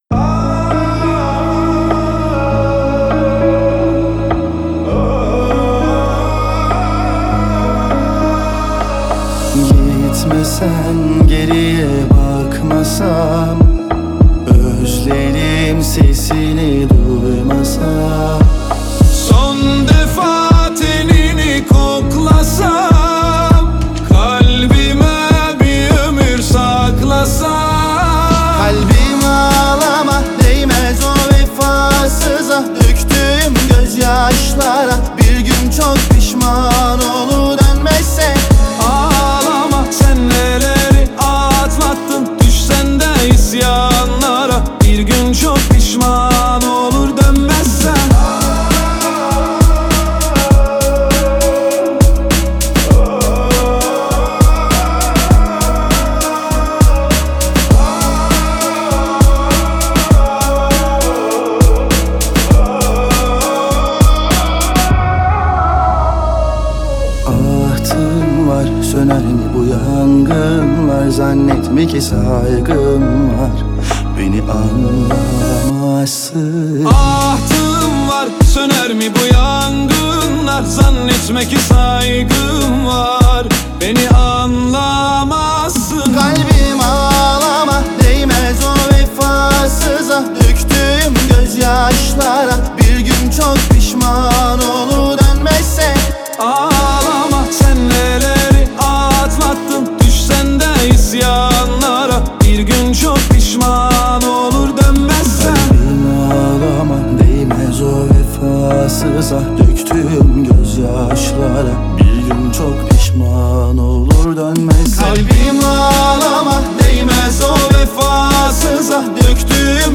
آهنگ ترکیه ای آهنگ غمگین ترکیه ای آهنگ هیت ترکیه ای ریمیکس